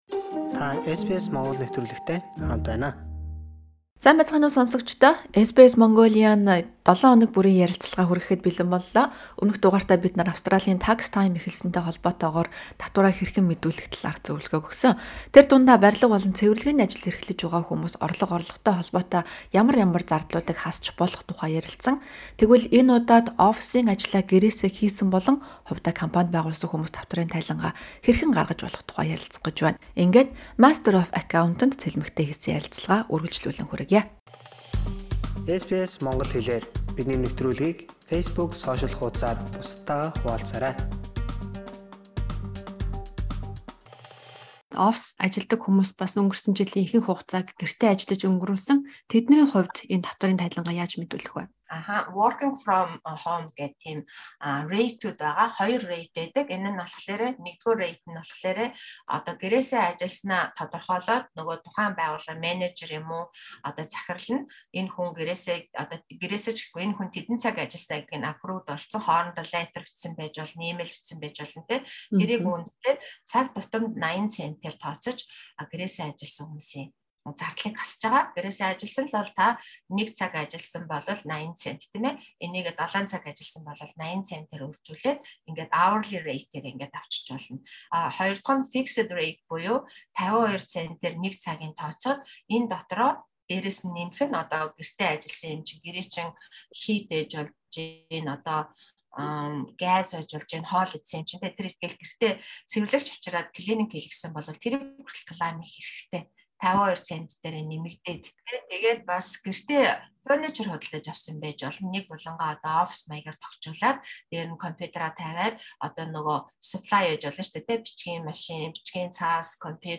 Энэ ярилцлагыг сонссоноор та: